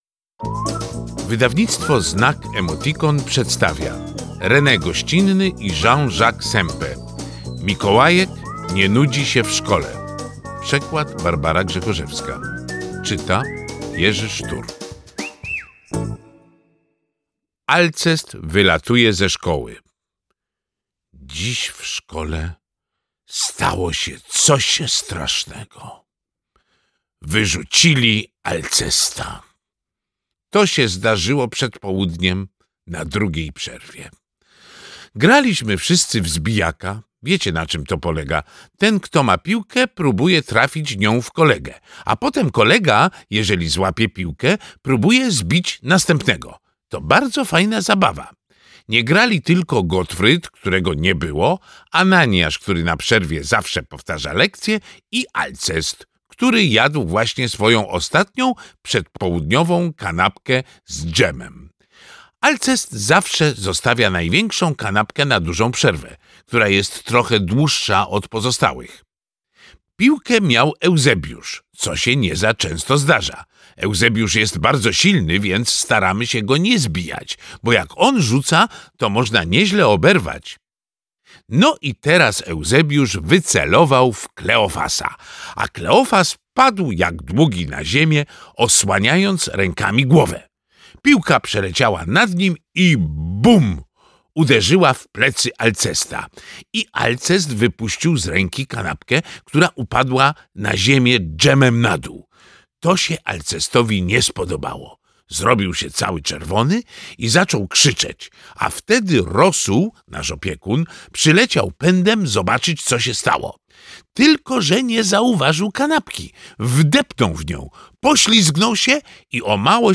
Mikołajek nie nudzi się w szkole - Rene Goscinny, Jean-Jacques Sempe - audiobook